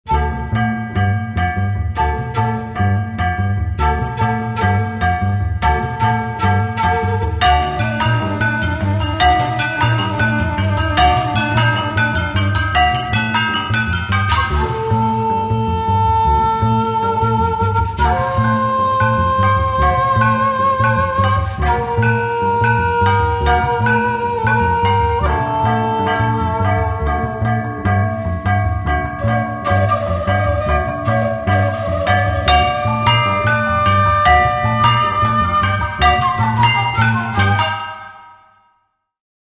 Australian classical music
Australian, Cross-cultural